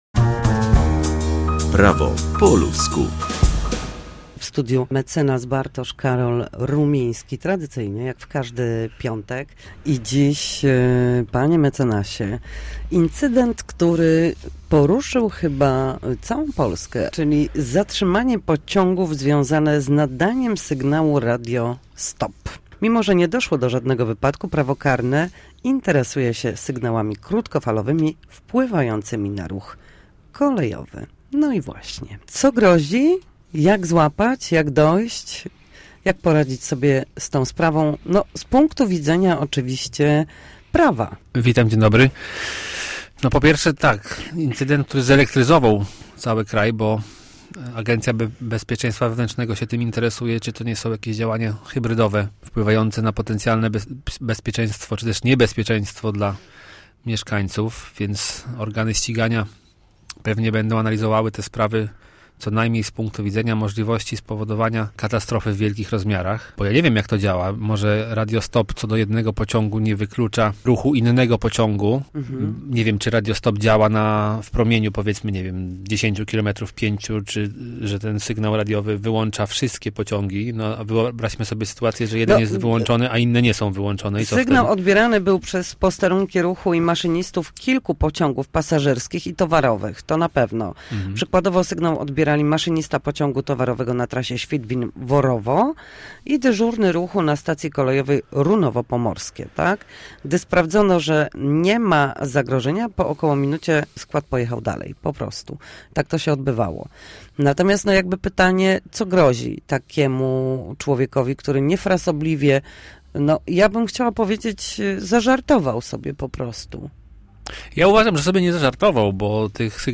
Nasi goście, prawnicy, odpowiadają na jedno pytanie dotyczące zachowania w sądzie czy podstawowych zagadnień prawniczych.